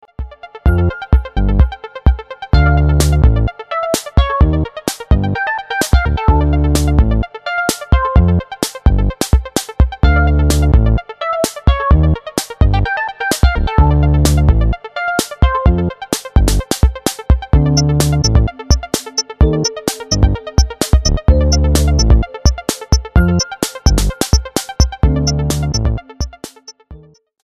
He makes freaked out Italo Disco that’s ahead of its time.